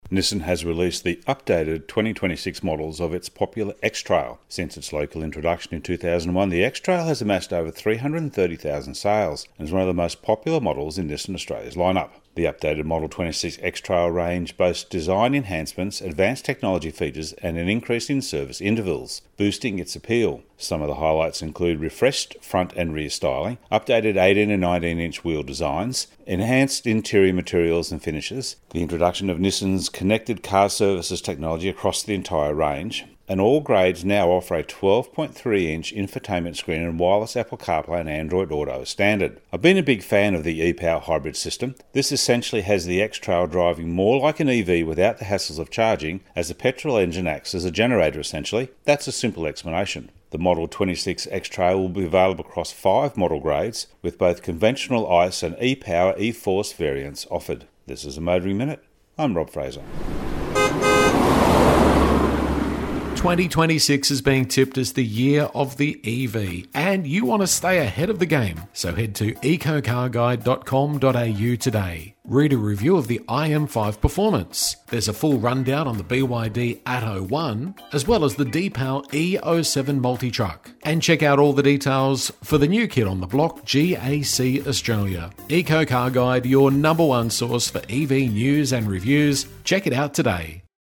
Motoring Minute is heard around Australia every day on over 120 radio channels.